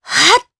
Cleo-Vox_Casting3_jp.wav